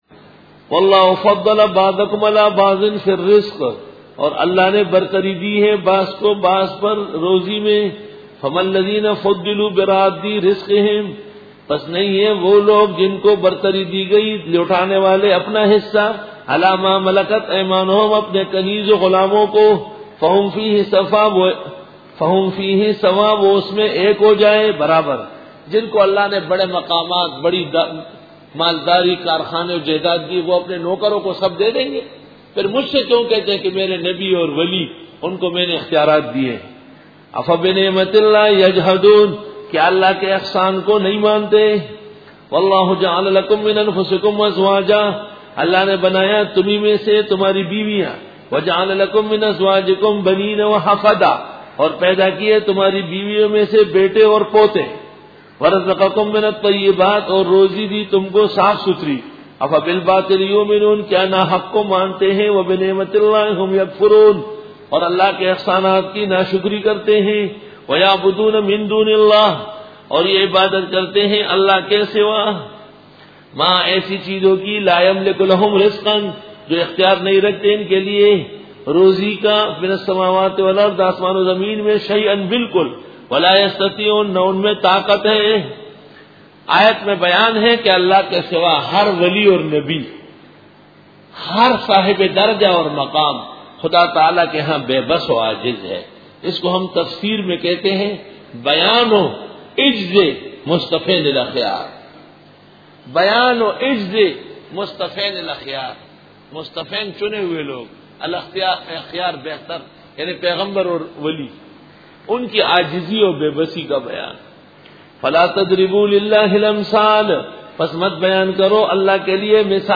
سورۃ النحل رکوع-10 Bayan